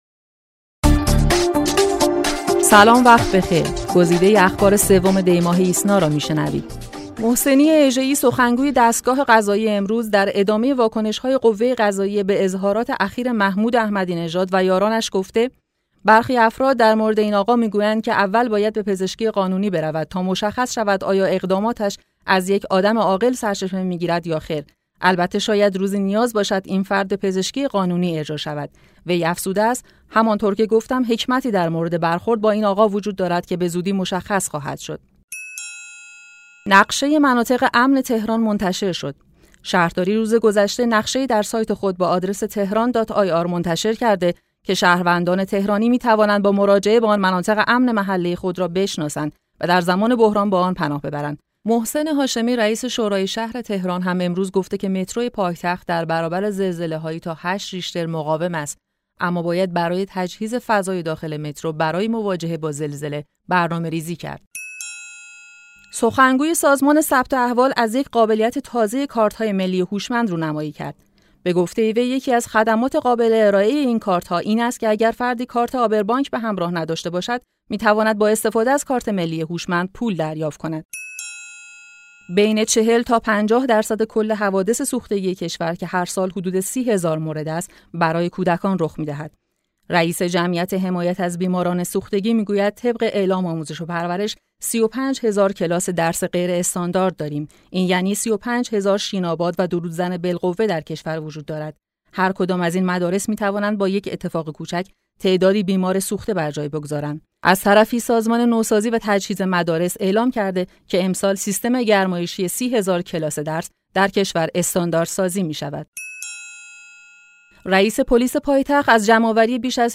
صوت / بسته خبری ۳ دی ۹۶